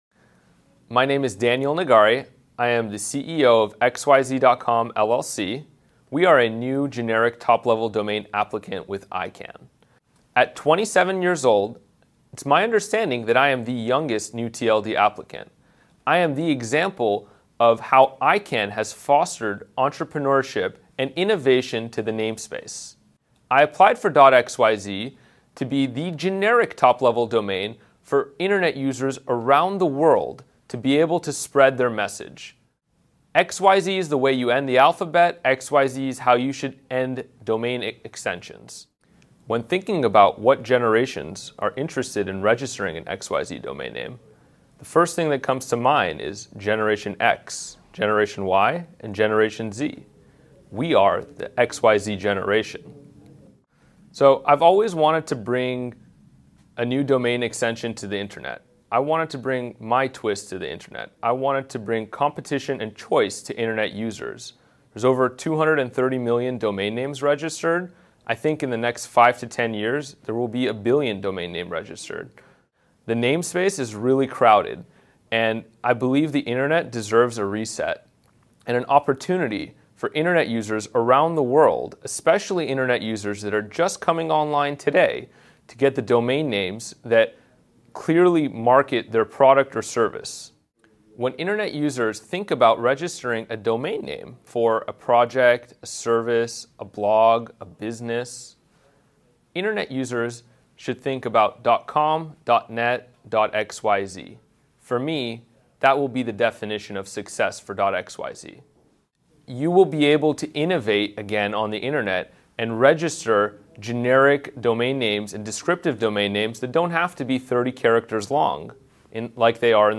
As part of ICANN's global program to raise awareness and interest in all of the New gTLDs, we invited all New gTLD applicants to participate in a series of recorded interviews. The short videos provide insight into the individual story of a TLD, or portfolio of TLDs, the inspiration behind it and how they see it being used.